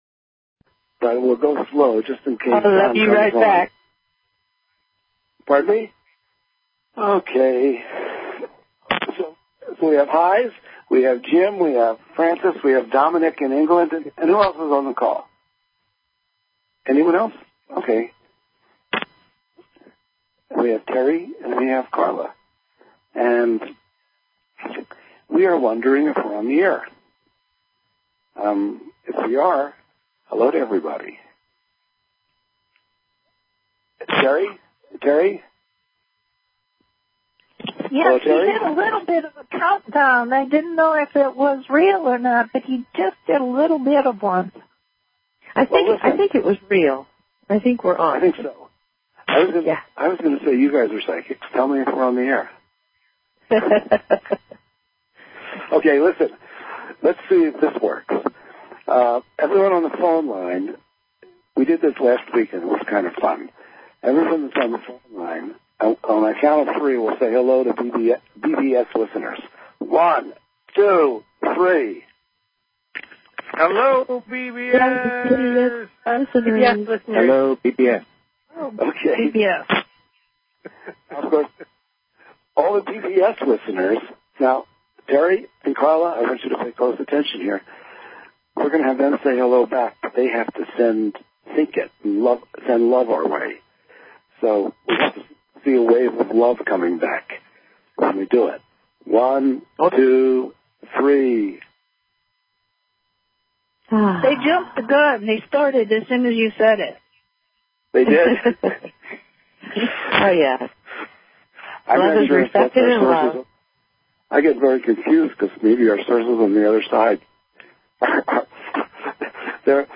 Talk Show Episode, Audio Podcast
We CALL IN THE LIGHT and listen to channeled messages from the Elohim!